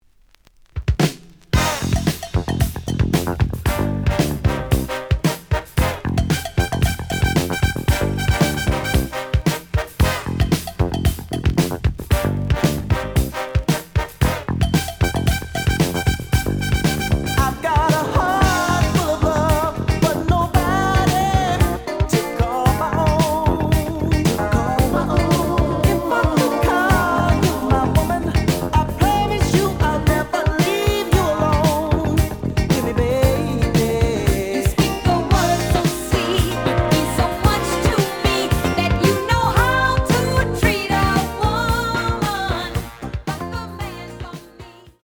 The audio sample is recorded from the actual item.
●Genre: Disco
●Record Grading: VG~VG+ (傷はあるが、プレイはおおむね良好。Plays good.)